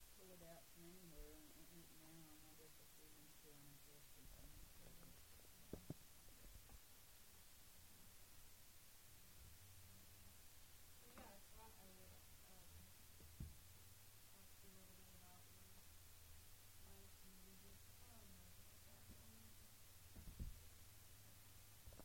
Charleston (W. Va.)